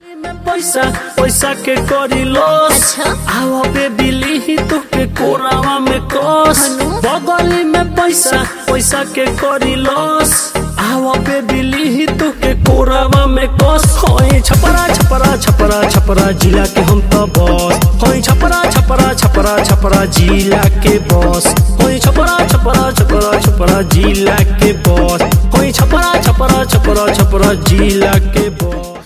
Ringtone File
Bhojpuri rap song